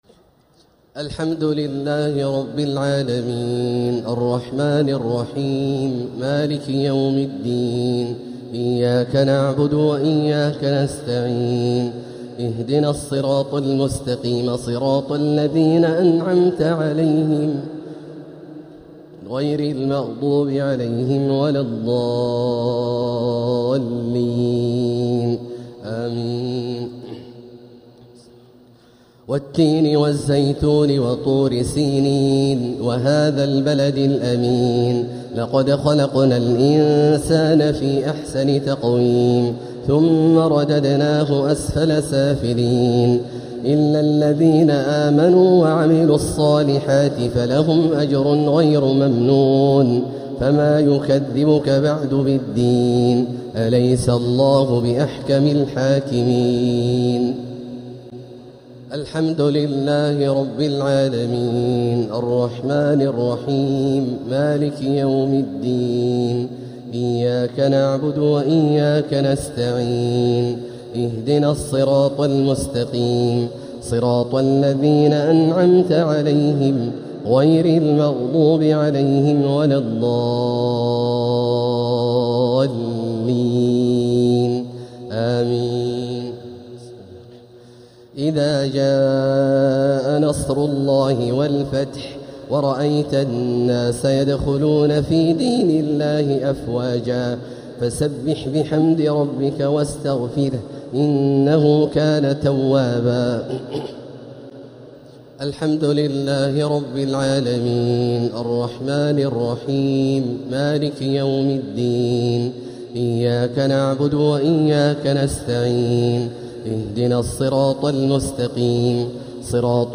صلاة الشفع و الوتر ليلة 5 رمضان 1447هـ > تراويح 1447هـ > التراويح - تلاوات عبدالله الجهني